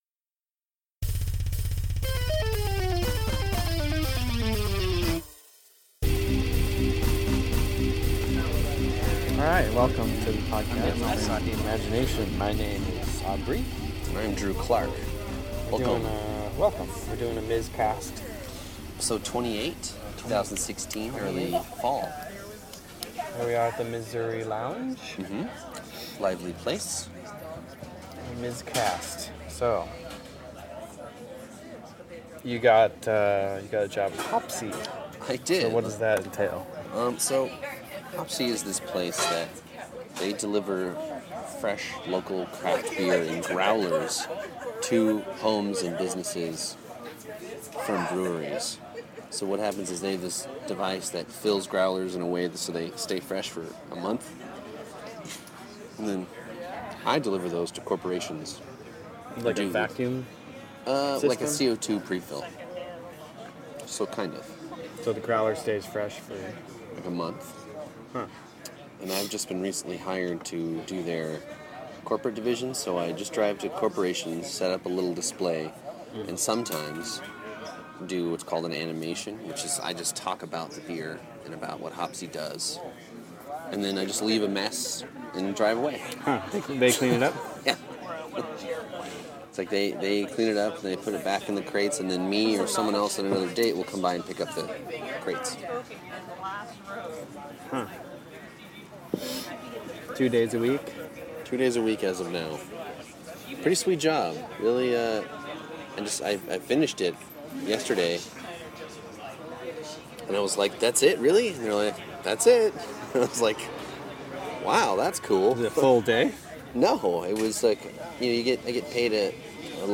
Download this episode Our final in a series of special Miz-cast episode from the Missouri Lounge in Berkeley, CA. This week, we’ve got an encouraging Gradient Update, and talk a bit about Patreon. Also, we talk about the phenomena of Bigfoot, UFOs, and the Loch Ness Monster.